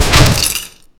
Chess Sound Effects - Free AI Generator & Downloads
Fantasy magical chess capture, stone chess piece shattering another piece into fragments, heavy impact with magical burst, dramatic cinematic sound, inspired by magical wizard chess, no voice